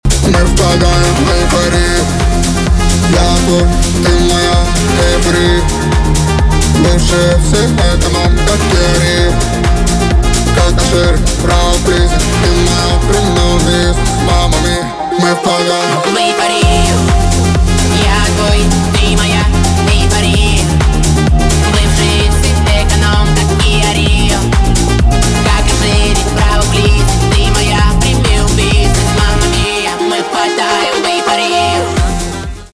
поп
танцевальные
басы